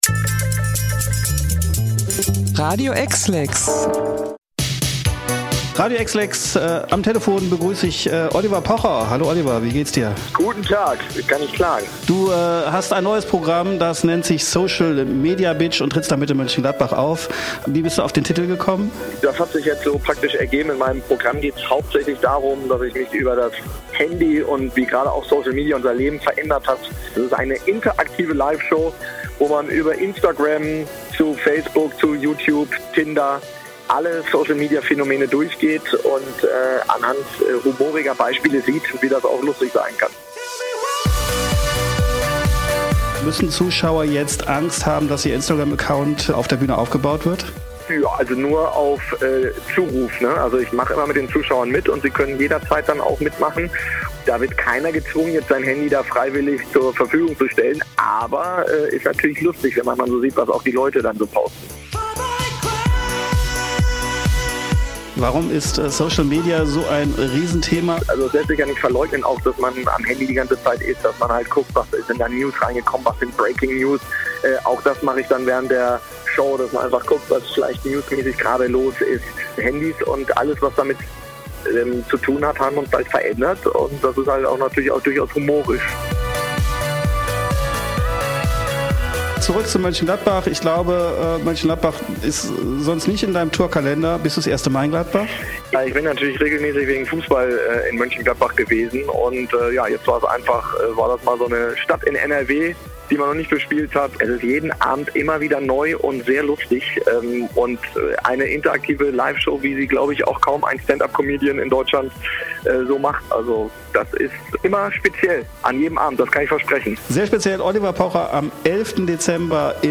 Interview-Oliver-Pocher-TB.mp3